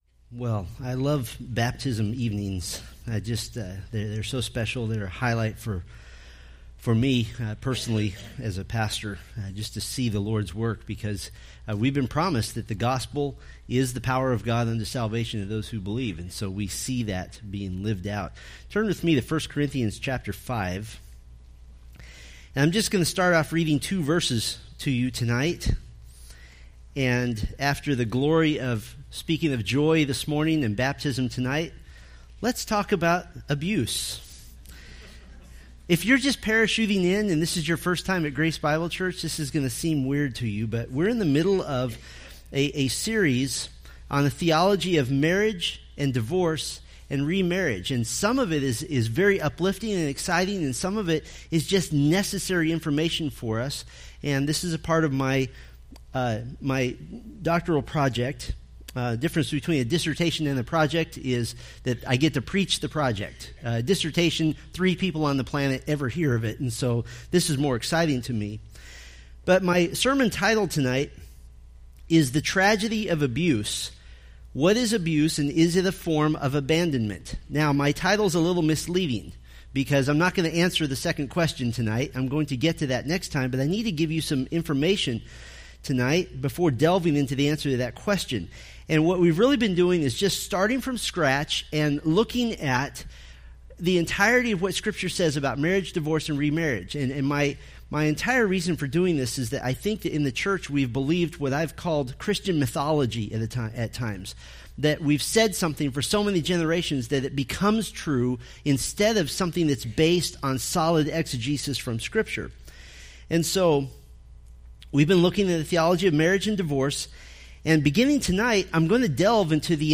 Preached November 5, 2017 from Selected Scriptures